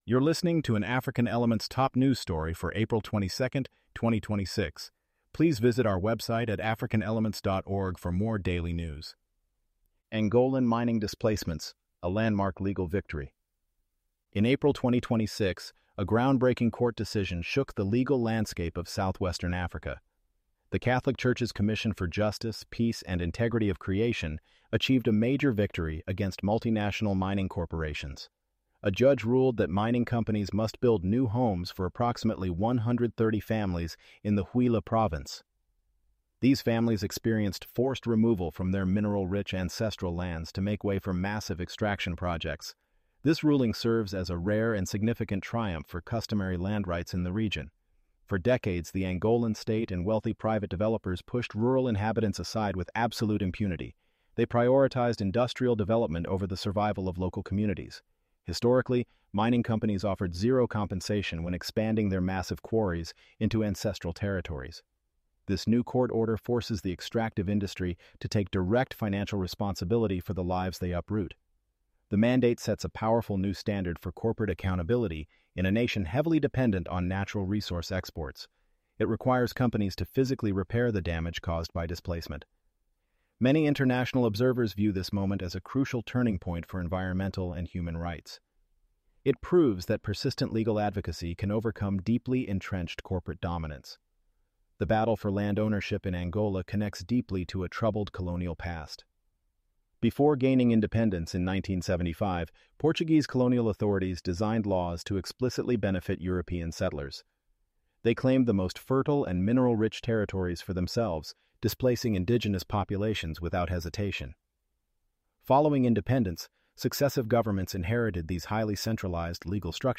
ElevenLabs_Angolan_Mining_Displacements-1.mp3